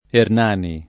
Ernani [ ern # ni ]